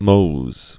(mōz)